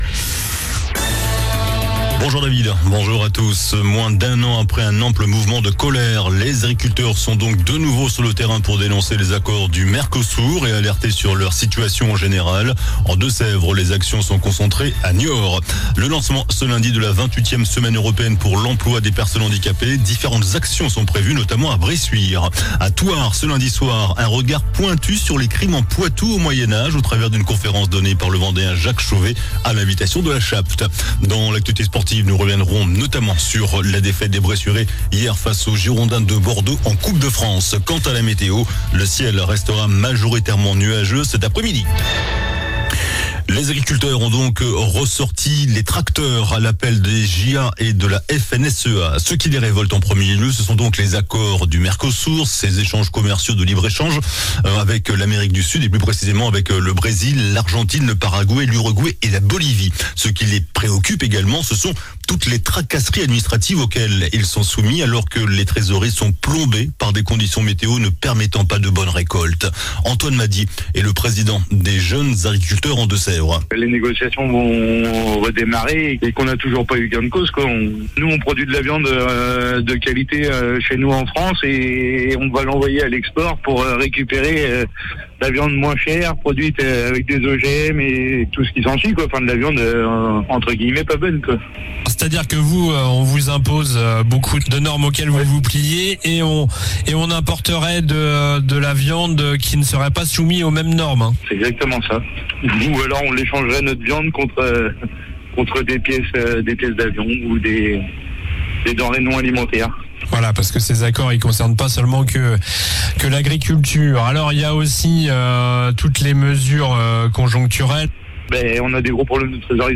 JOURNAL DU LUNDI 18 NOVEMBRE ( MIDI )